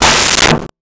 Metal Strike 06
Metal Strike 06.wav